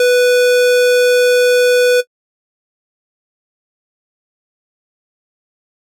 フリー効果音：アラート
システム系のアラート音を作ってみました！ピーッ！っていう音が印象的です！音量注意！